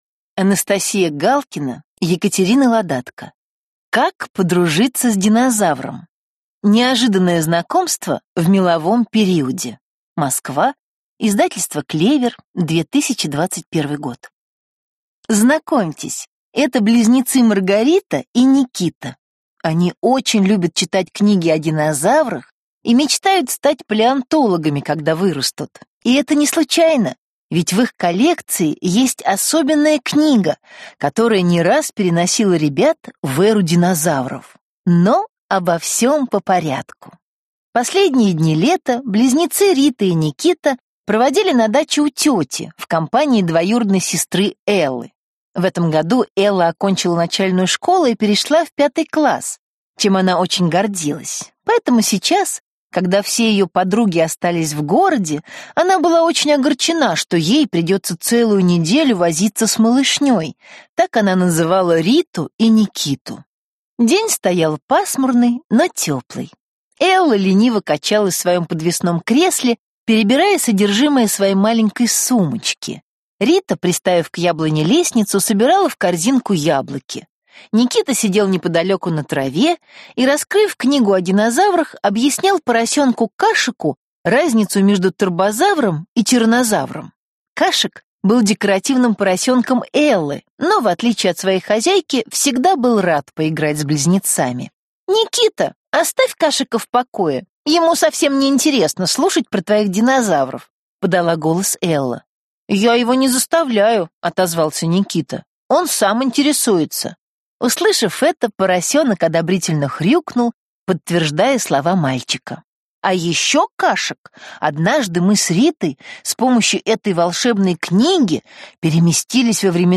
Аудиокнига Как подружиться с динозавром? Неожиданное знакомство в меловом периоде | Библиотека аудиокниг